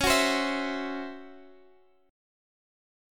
C#mM7bb5 chord